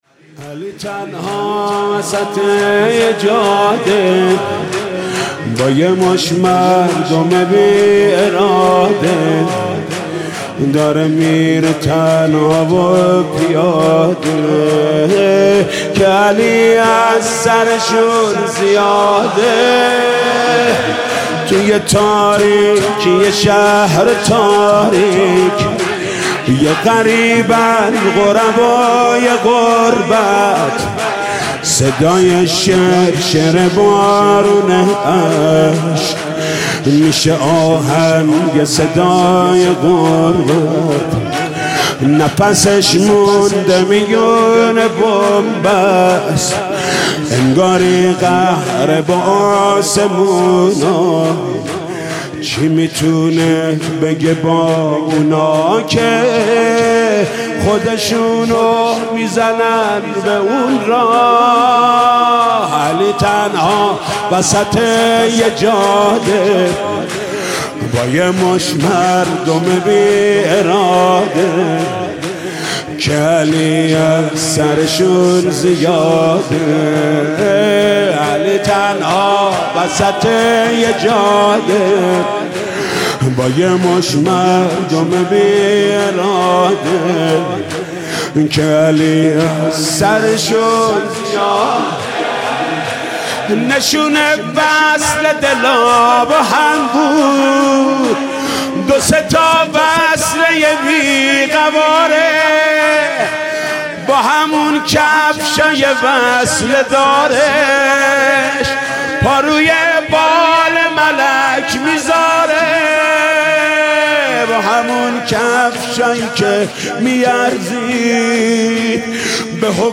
«شب بیستم» شور: علی تنها وسط یه جاده